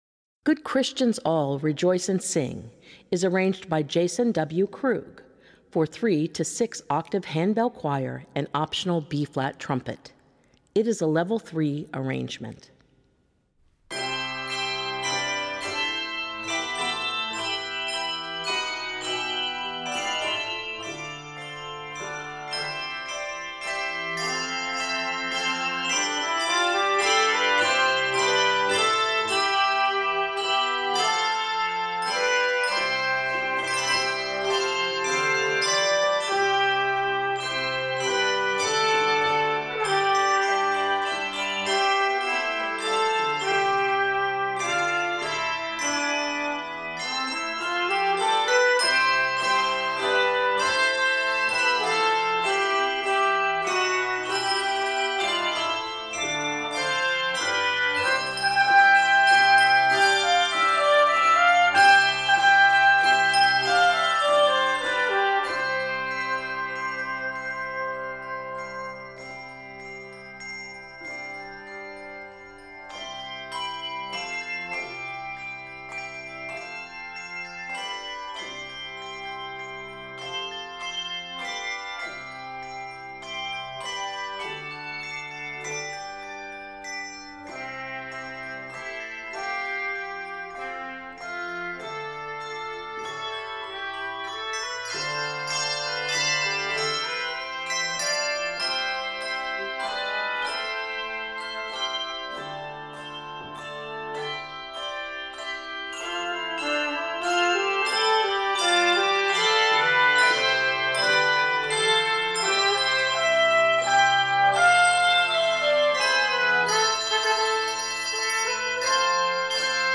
the feeling of Easter joy.
Key of C Major.